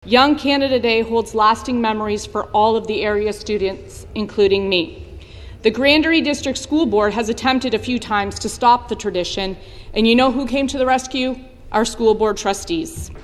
During debate, Brady highlighted the potential impact on local traditions and community events that have been protected by local trustees.